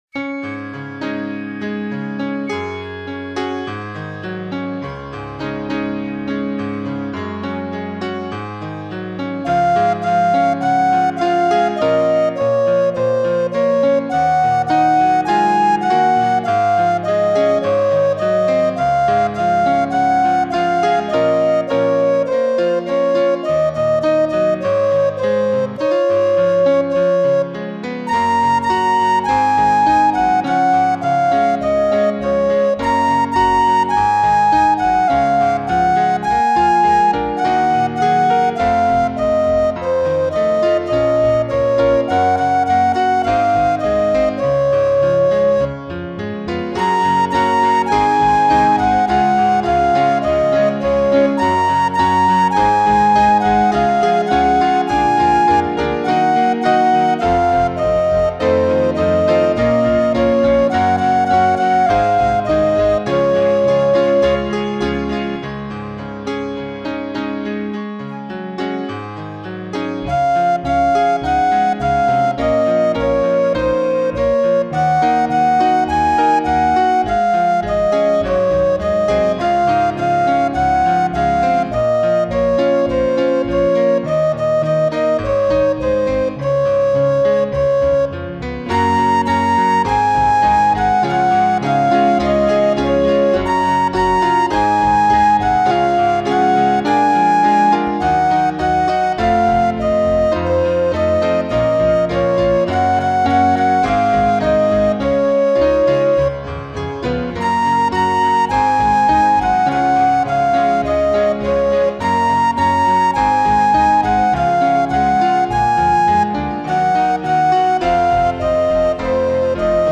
wersja instrumentalna